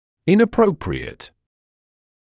[ˌɪnə’prəuprɪət][ˌинэ’проуприэт]неподходящий, неуместный